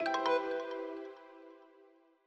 Longhorn 8 - Notify Email.wav